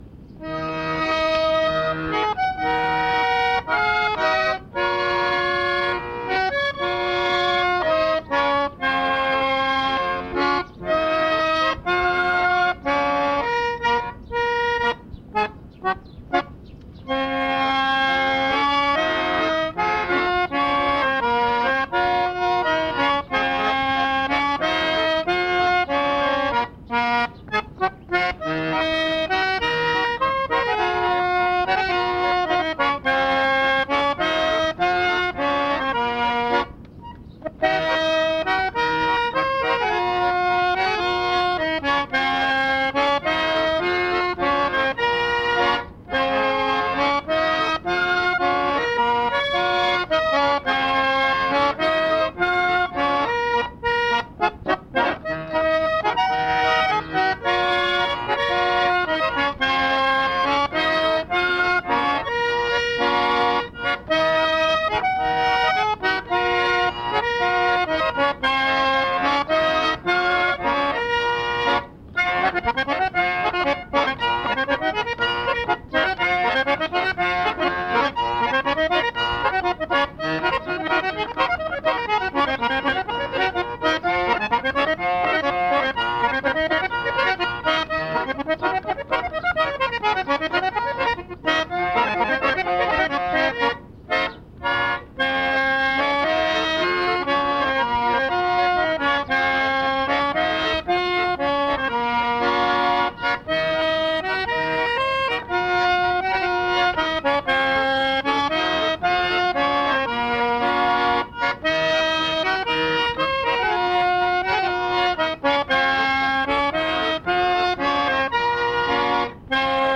гармонь